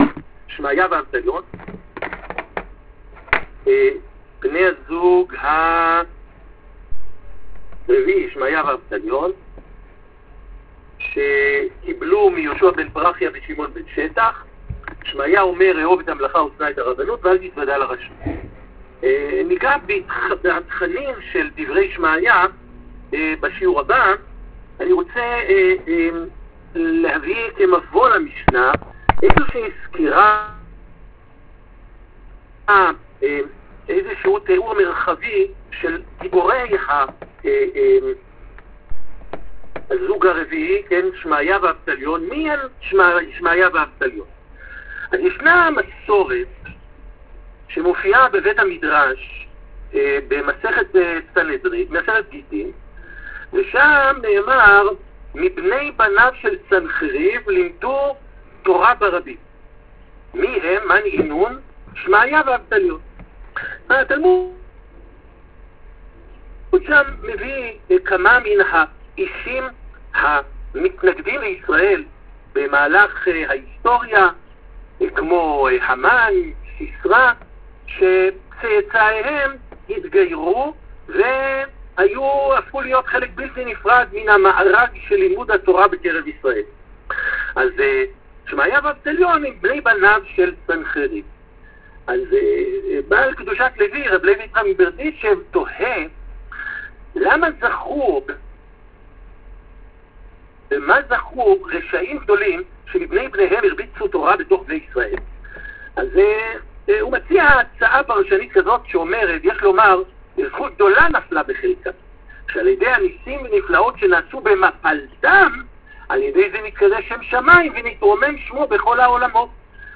שיעור על תולדותיהם של שמעיה ואבטליון מצגת נלווית